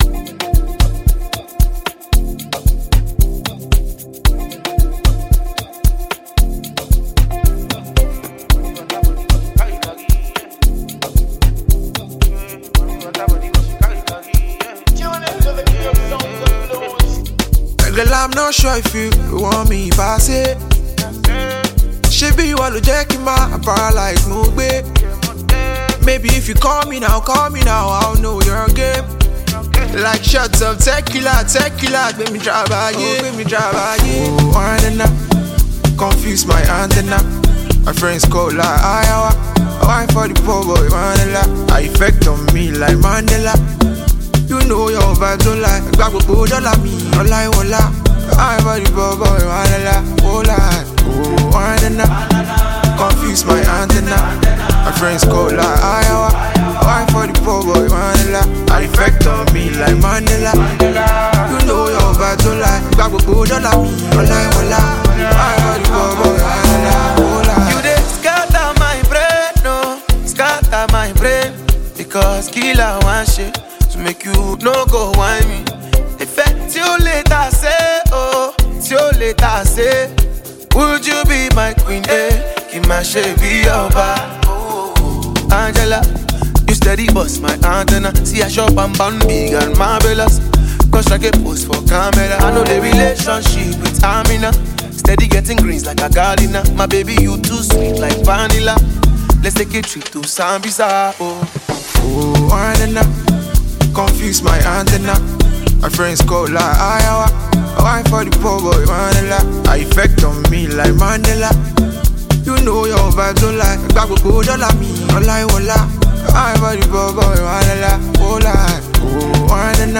smooth-sounding hit
African Music Genre: Afrobeats Released